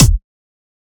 Kick 2 (Right in).wav